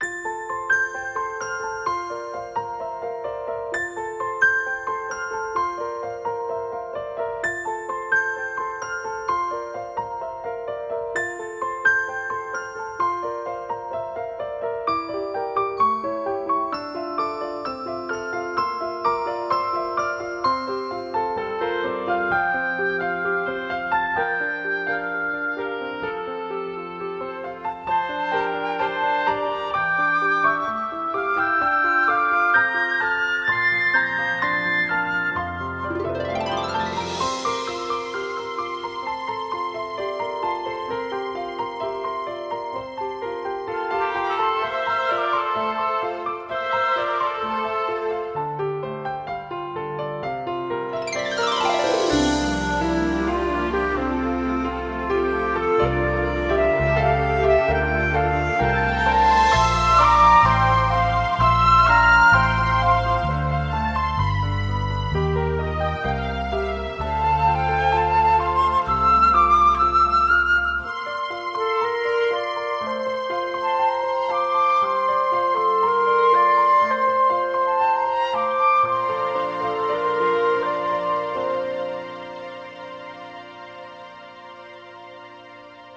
Zmam tytuł, ale nie znalazłem wykonawcy, muzyka z fortepianem i orkiestrą, myślałem że z z alladyna, ale nie.